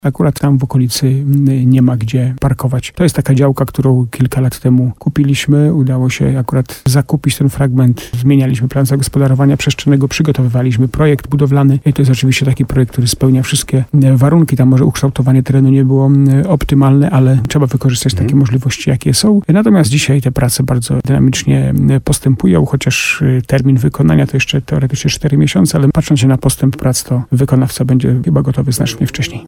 Rozmowa z Jackiem Lelkiem: Tagi: Nowy Sącz Słowo za Słowo budowa Jacek Lelek Stary Sącz Parking HOT Cmentarz Komunalny